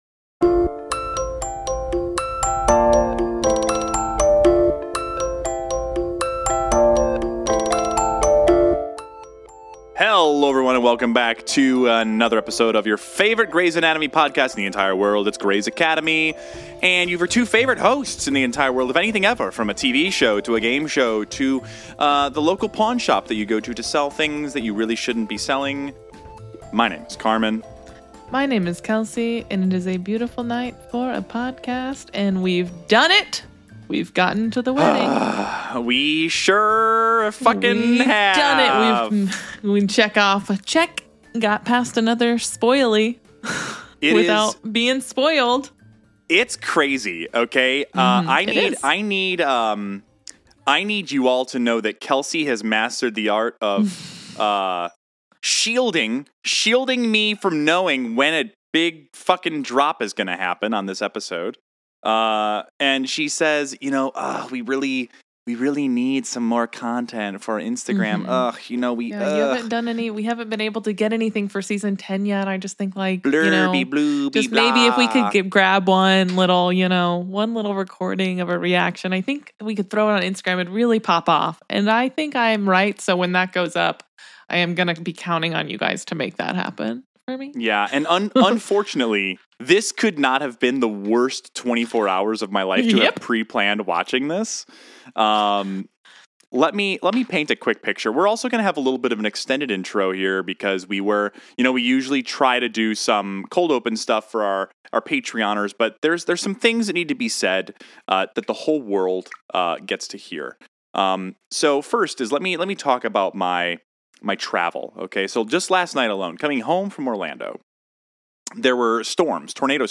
Disclaimer: Skip ahead like 20 minutes if you don't want to hear all the banter.